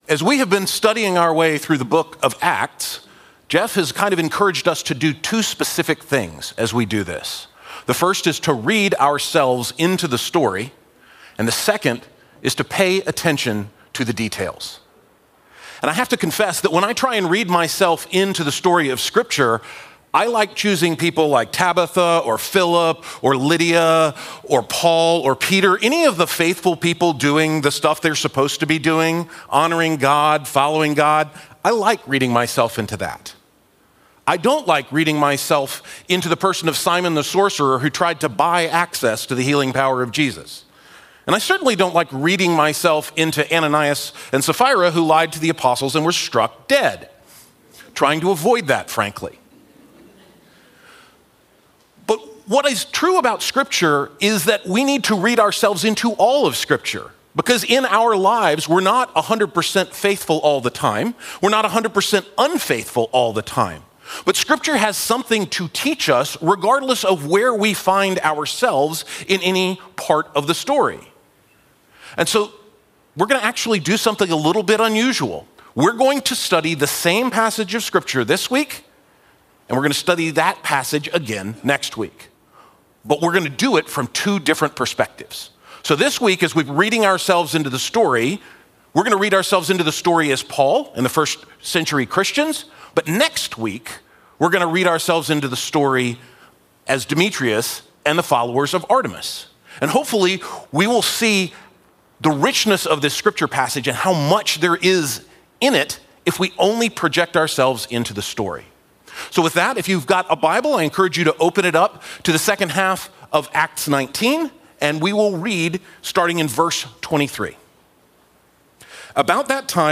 Acts 19:23-31 – guest speaker